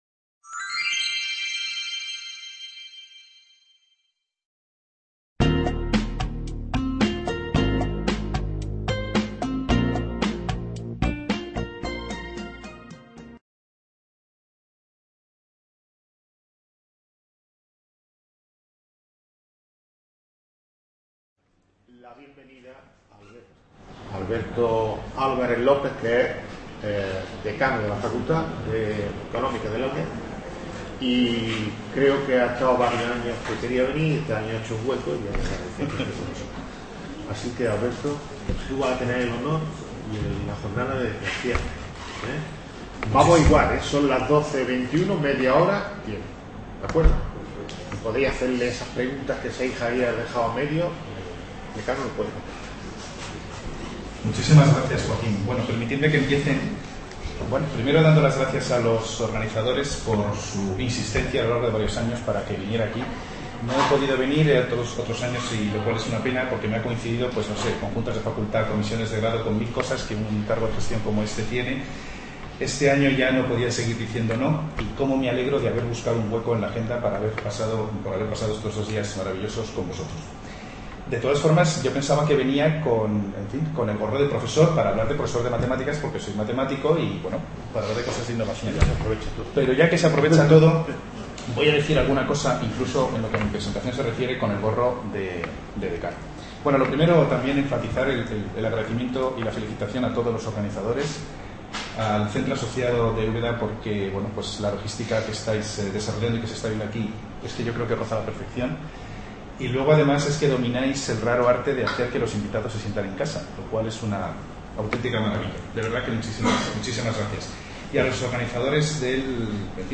conferencia
IV Jornadas de Experiencias e Innovación Docente en Estadística y Matemáticas (EXIDO)